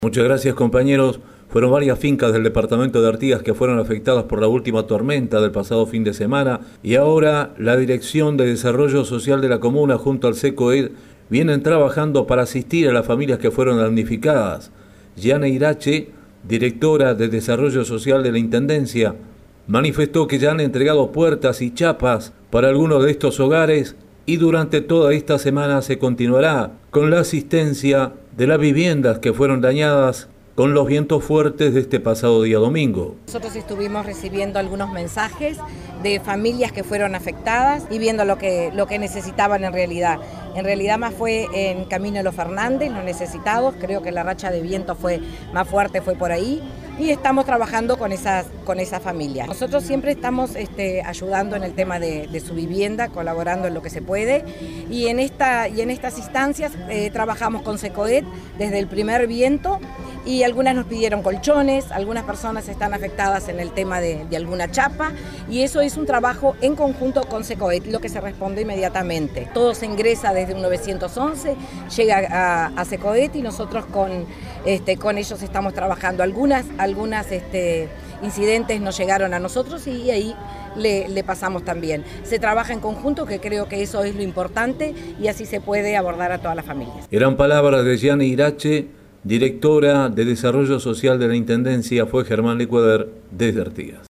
Informe del corresponsal